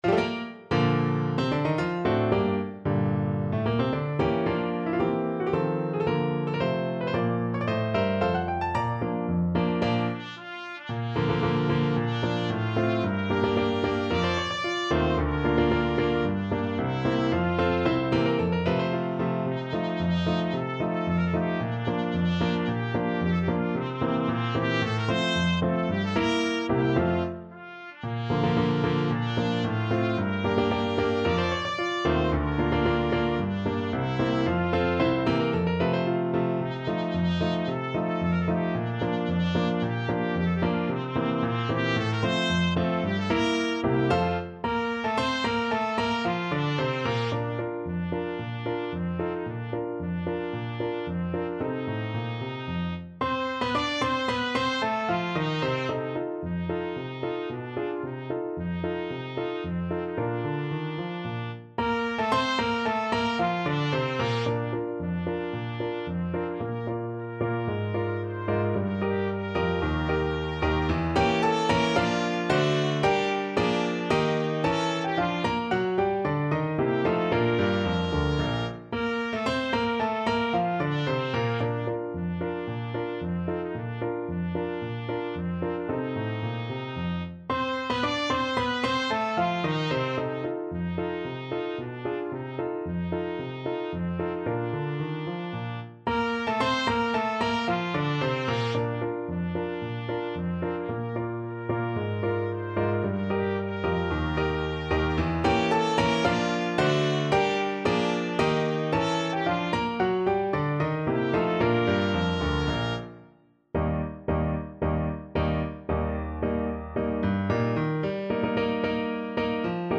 Free Sheet music for Trumpet Duet
Bb major (Sounding Pitch) C major (Trumpet in Bb) (View more Bb major Music for Trumpet Duet )
March =c.112